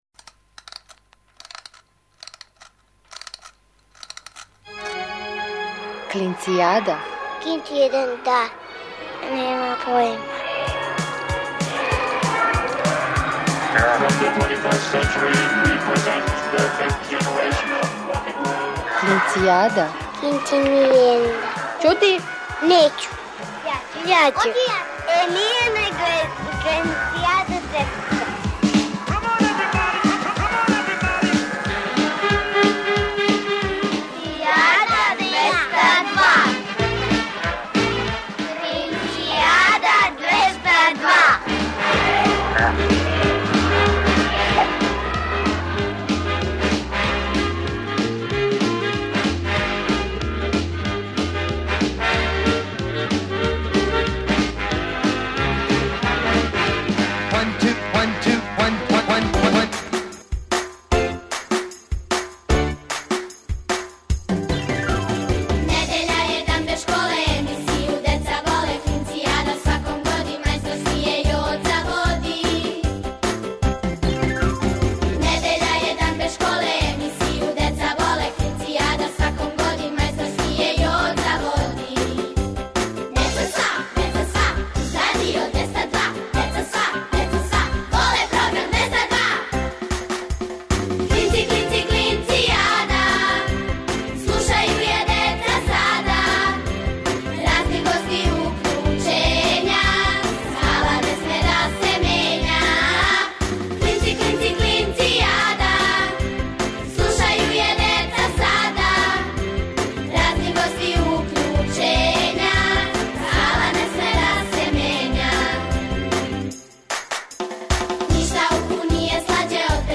Сваке недеље уживајте у великим причама малих људи, бајкама, дечјим песмицама.